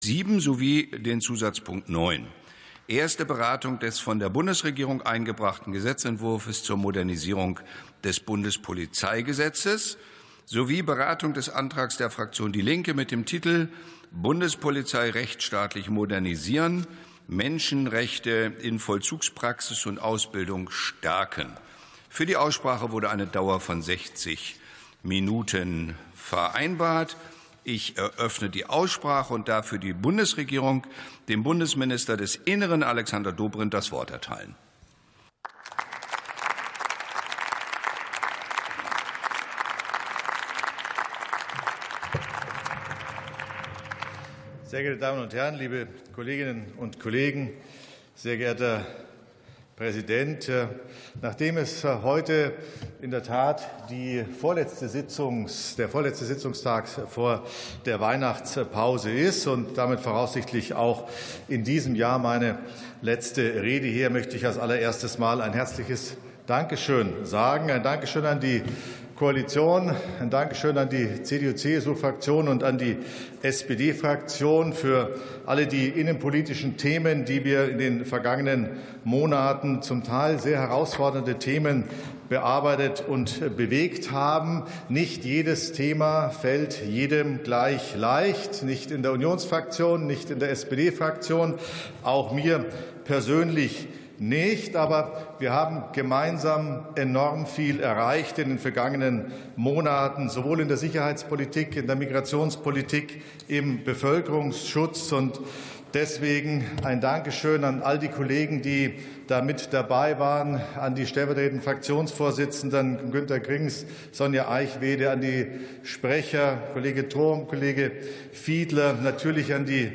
50. Sitzung vom 18.12.2025. TOP 7, ZP 9: Modernisierung des Bundespolizeigesetzes ~ Plenarsitzungen - Audio Podcasts Podcast